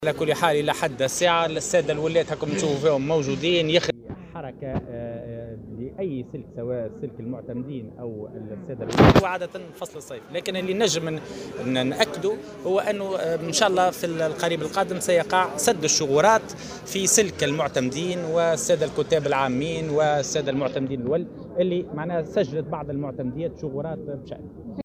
ونفى الفراتي في تصريح اليوم لـ"الجوهرة أف أم" على هامش اجتماع استثنائي للولاة بسوسة، القيام بأي تحوير في سلك الولاة حاليا، مؤكدا أن التحوير في هذا السلك يتم عادة في فصل الصيف.